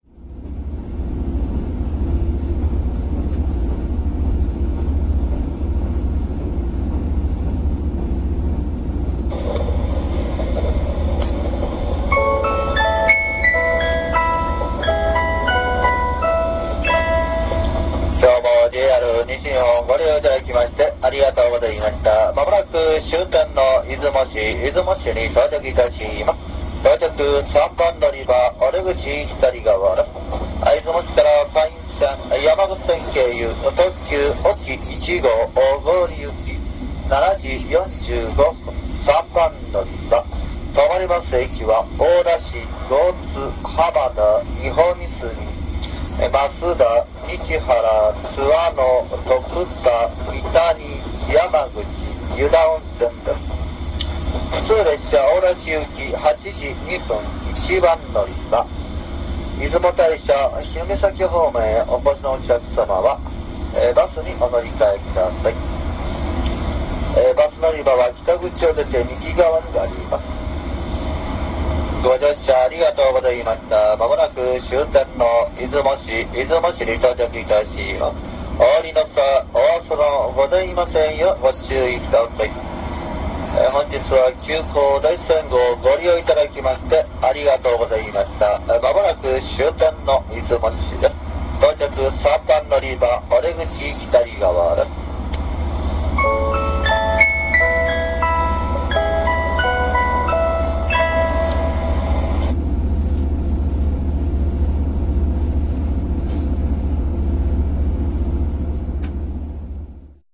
だいせんが終点出雲市駅に到着するときの車内アナウンスです。
最前部の1号車、スハネフ15の客室内での録音です。